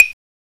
Index of /m8-backup/M8/Samples/Fairlight CMI/IIX/PERCUSN1
STICK.WAV